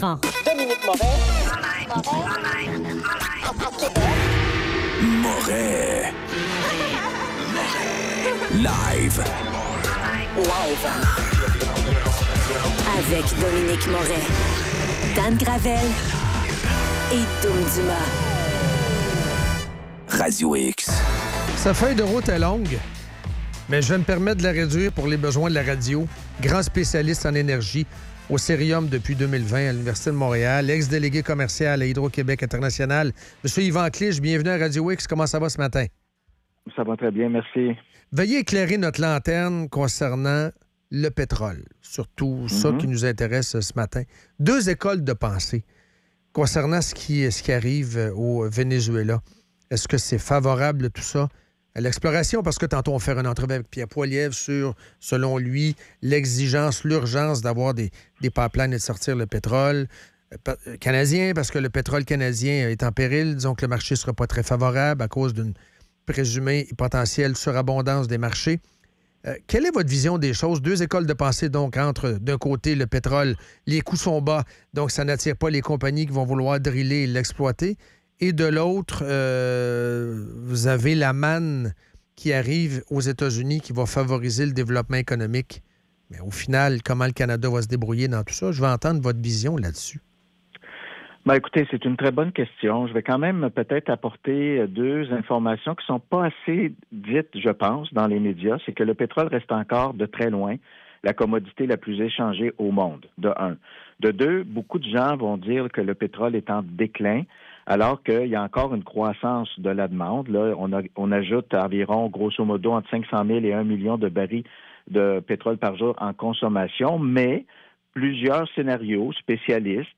En entrevue